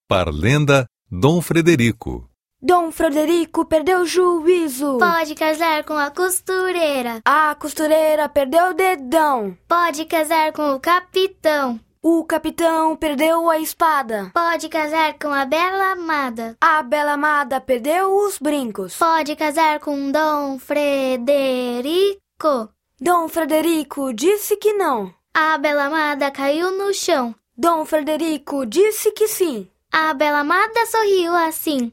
Parlenda "Dom Frederico"